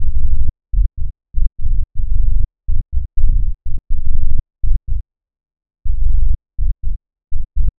• tech house bass samples - F#m - 123.wav
tech_house_bass_samples_-_F_sharp_m_-_123_bV2.wav